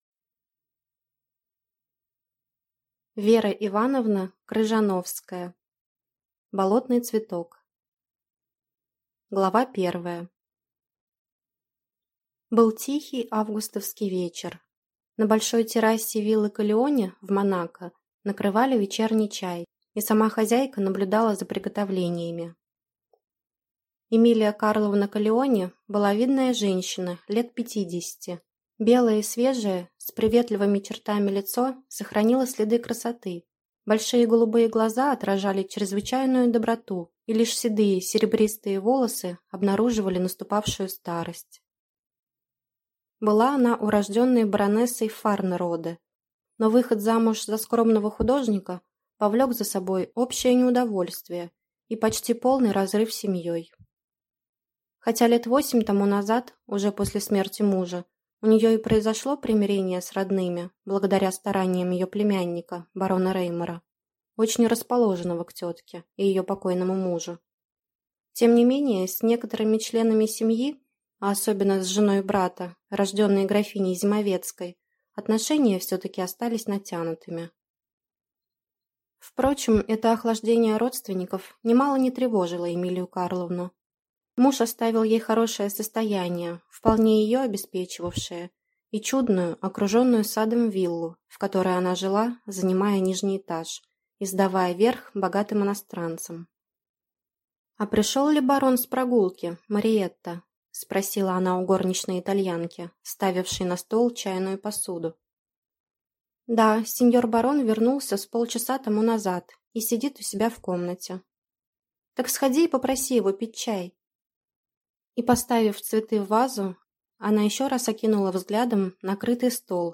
Аудиокнига Болотный цветок | Библиотека аудиокниг
Прослушать и бесплатно скачать фрагмент аудиокниги